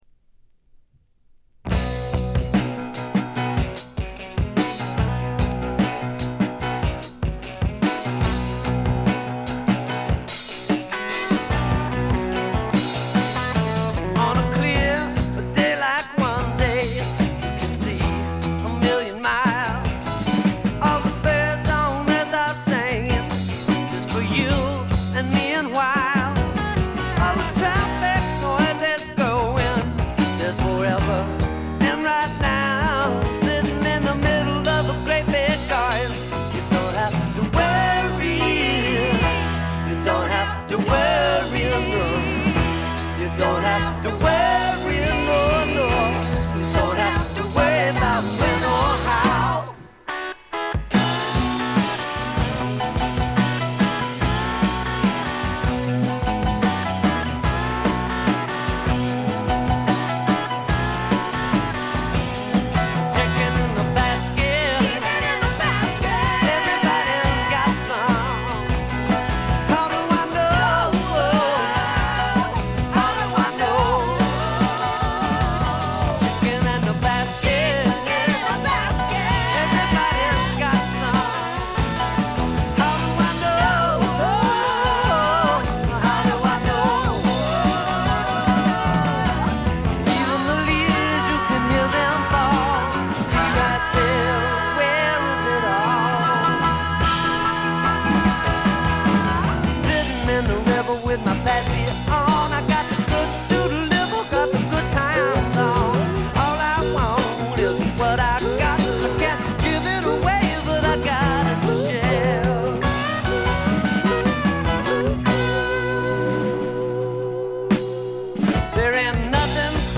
ほとんどノイズ感無し。
才気あふれるスワンプサウンドという感じ。
試聴曲は現品からの取り込み音源です。